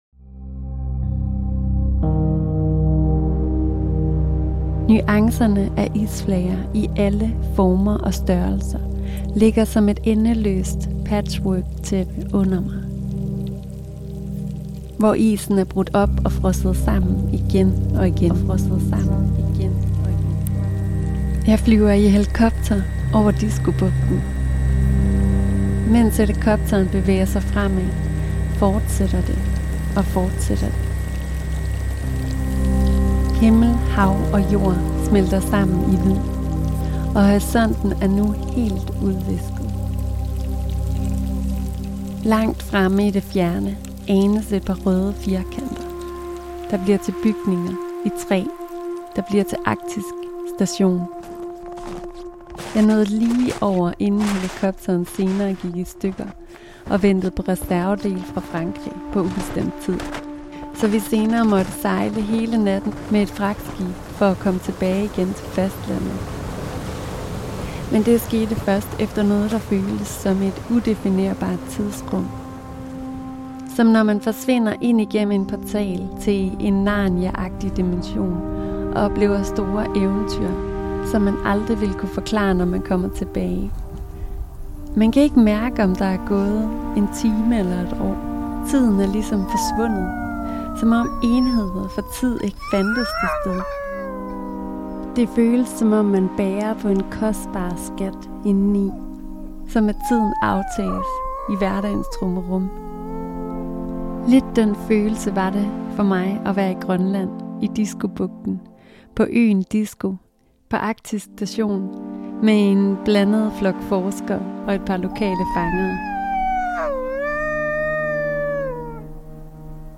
Denne sæson af Lyden Af Videnskab er nok noget af det nærmeste podcastserien har været på netop lyden af videnskab, når det udføres. Du vil tages med på forskning i det arktiske hav og på land og det der er lige imellem, nemlig isen.